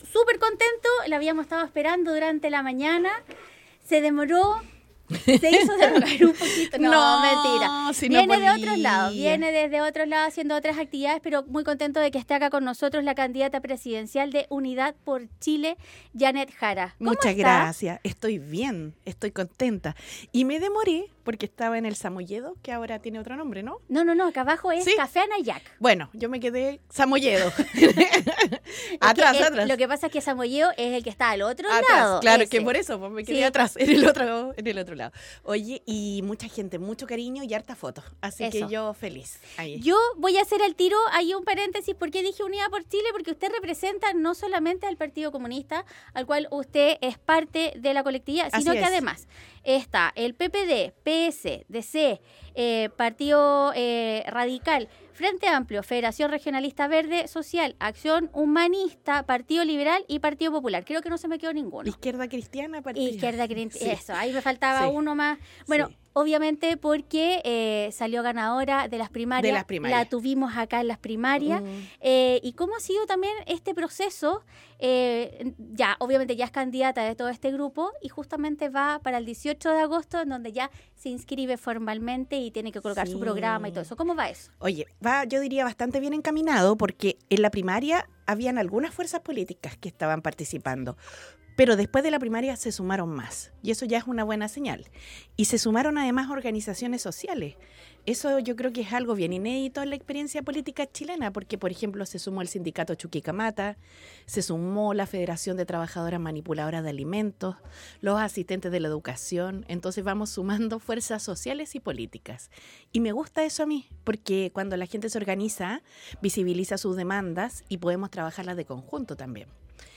Candidata Presidencial Jeannette Jara Visita los estudios de Radio Festival